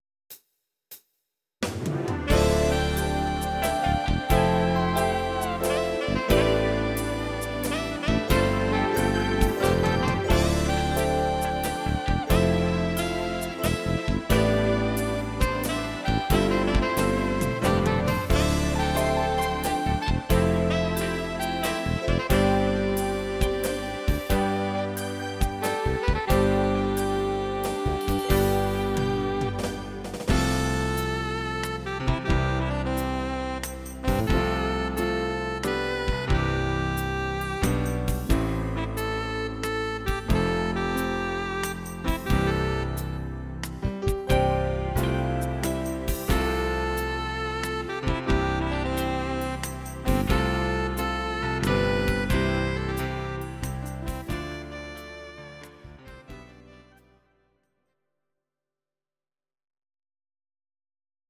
Audio Recordings based on Midi-files
Pop, Rock, 2000s